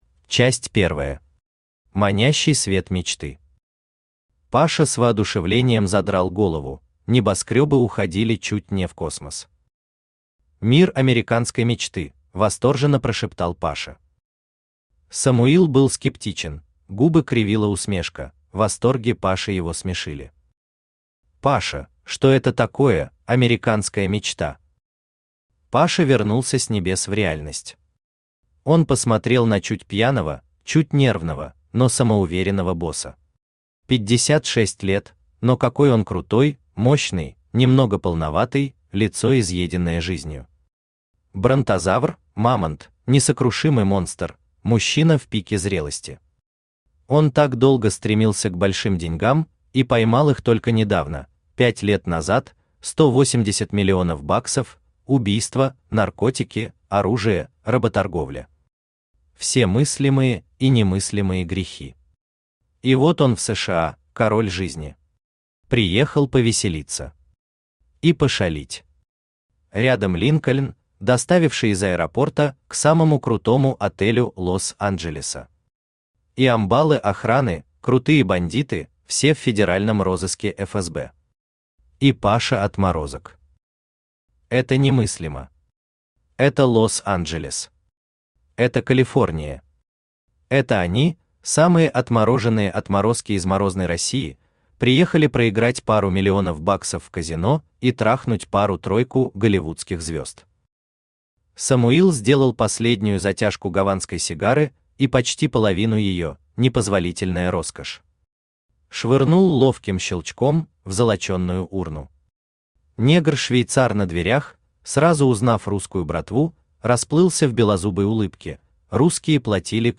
Приключения в США, Мексике и морозной России.Роман составляет дилогию вместе с романом «Извращённость».Содержит нецензурную брань.